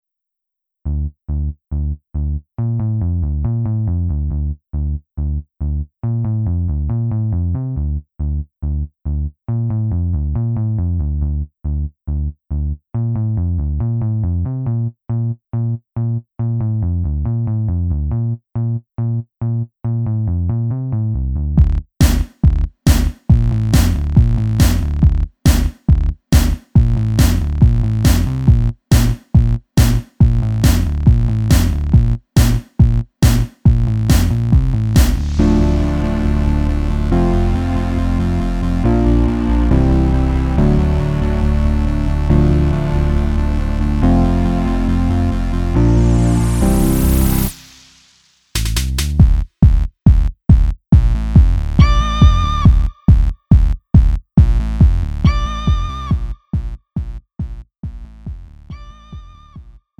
음정 -1키 3:24
장르 가요 구분 Lite MR